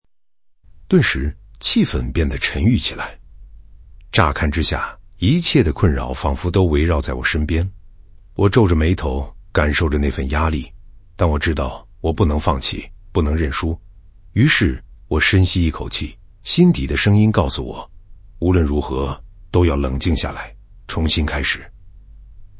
接下来给大家带来的是字节跳动的最新技术，Seed-TTS，一种文本到语音（TTS）模型系列，能生成高质量、几乎和真人语音没两样的语音模型。